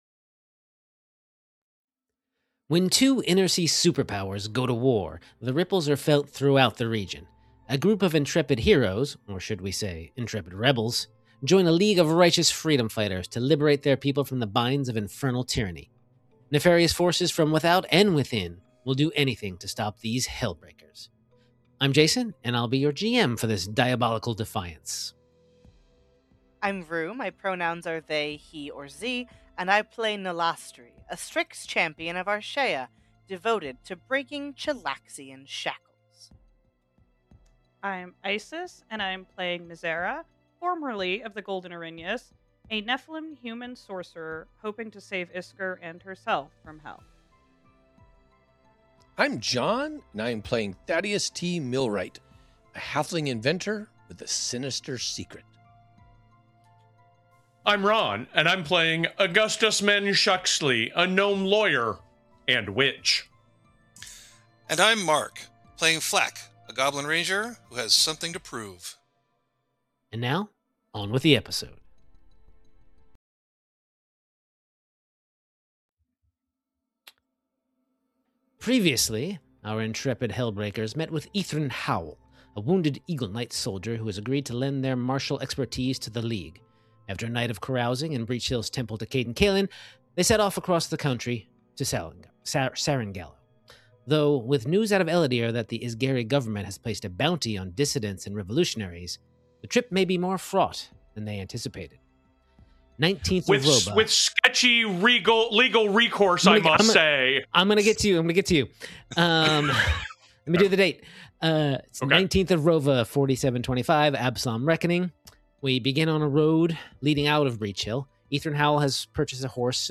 Actual Play Podcast